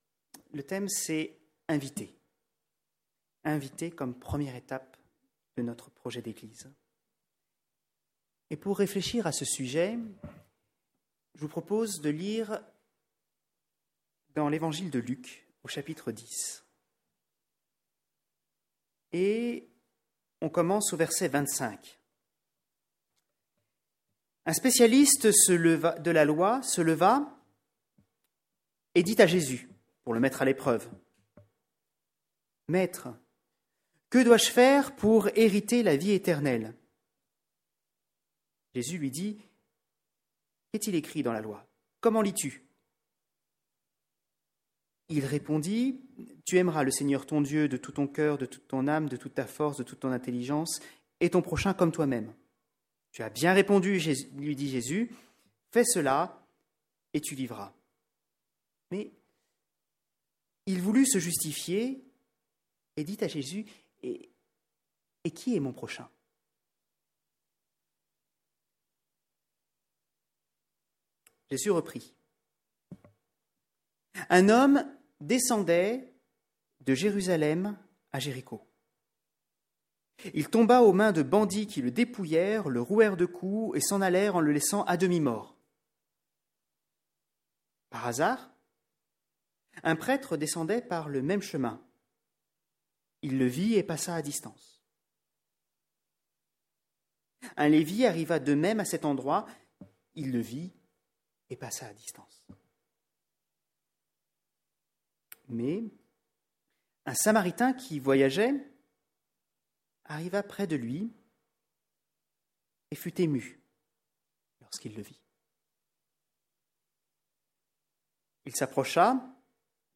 Sujet de la prédication